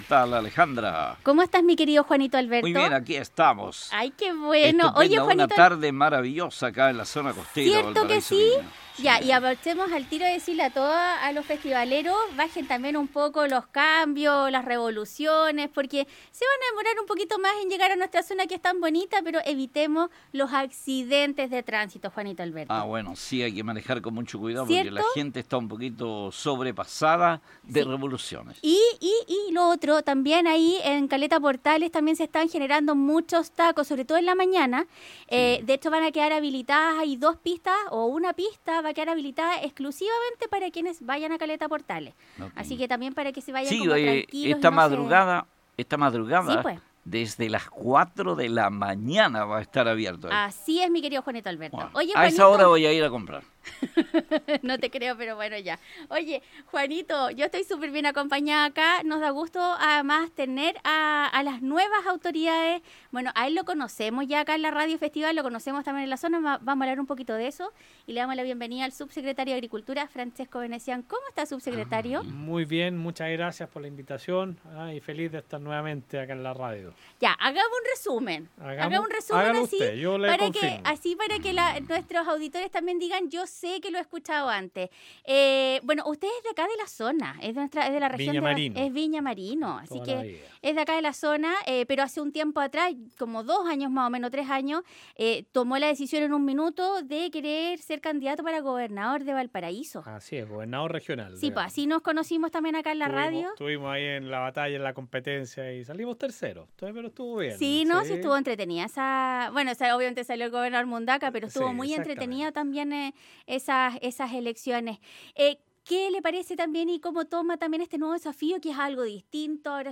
El Subsecretario de Agricultura Francesco Venezian estuvo en los estudios de Radio Festival para contar detalles de la recuperación del Jardín Botánico y como enfrentarán los desafíos del Agro en la Región.